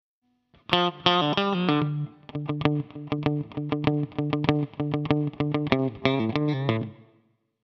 These simulation are optimal for the overall tone, really near the original also for the sustain.
In each file I recorded the same phrase with a looper: first with the Original MXR DynaComp inserted at the beginning of the chain and a second recording with the Kemper Stomps.
DEMO-DYNACOMP-KPA-6.mp3